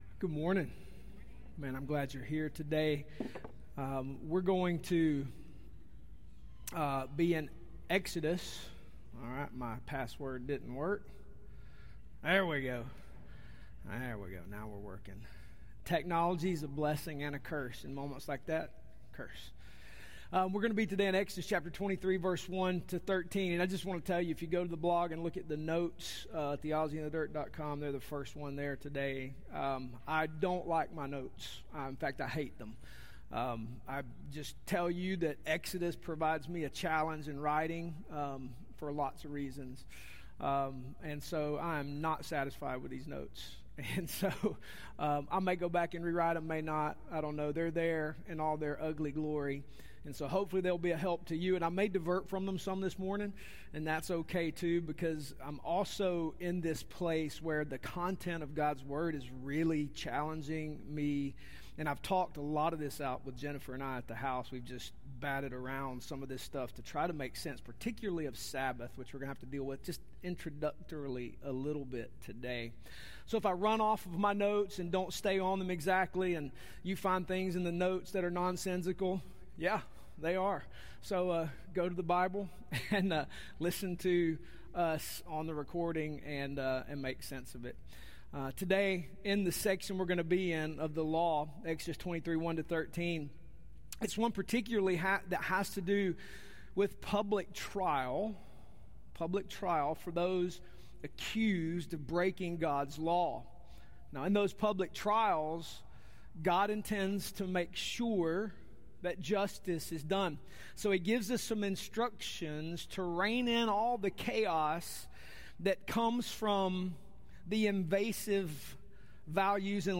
This sermon explores Exodus 23:1-13. Our section of the law today is one that has to do with public trial for those accused of breaking God’s law.